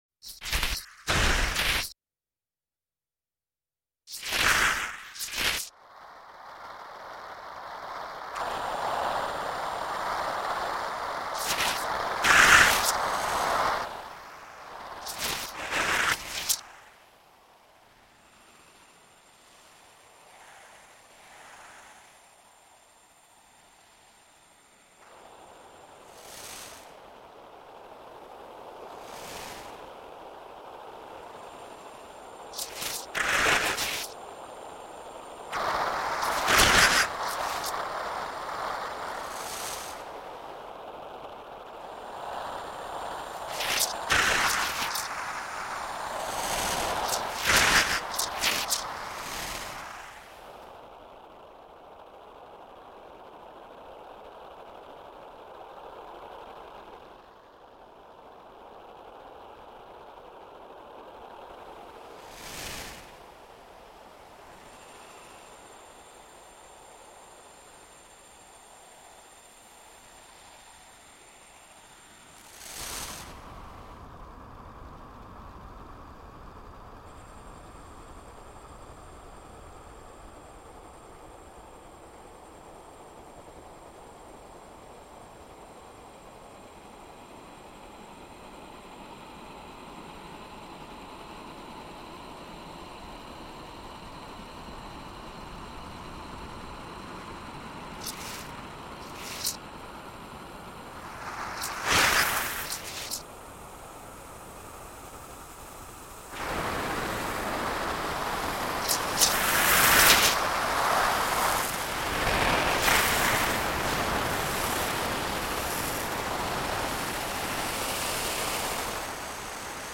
Œuvre électronique 1998